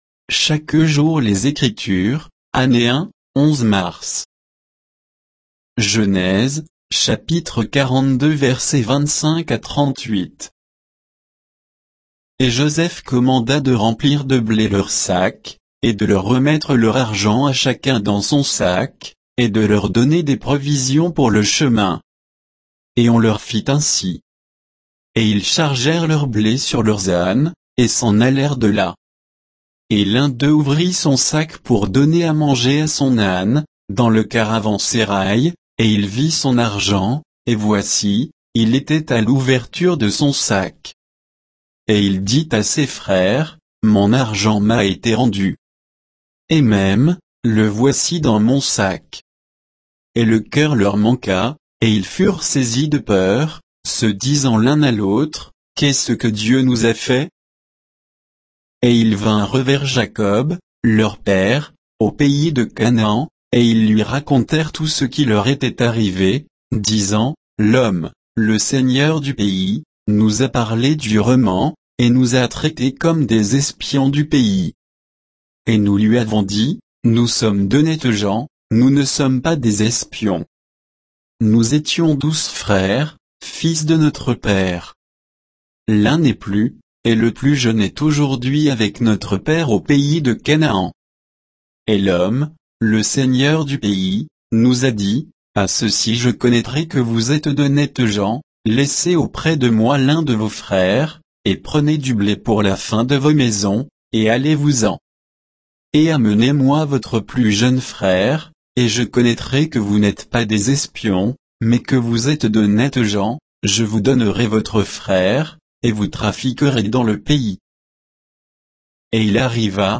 Méditation quoditienne de Chaque jour les Écritures sur Genèse 42